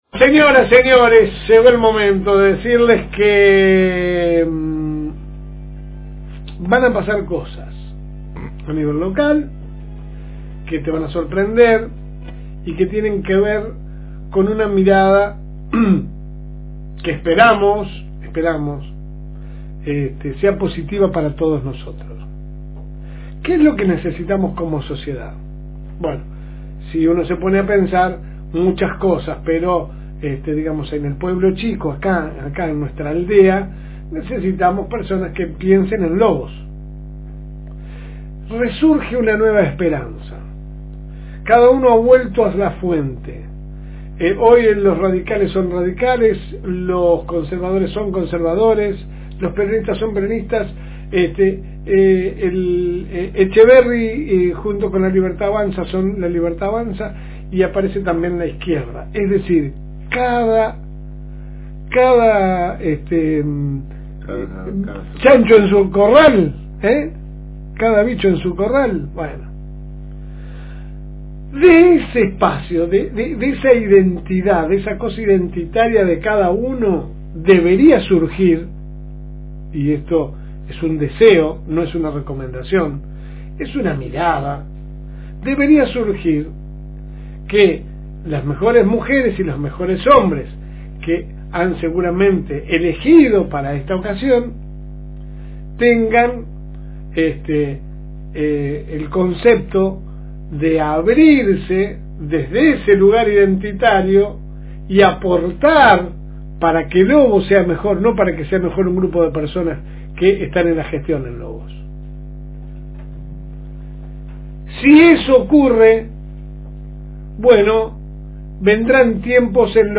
Compartimos con ustedes la última editorial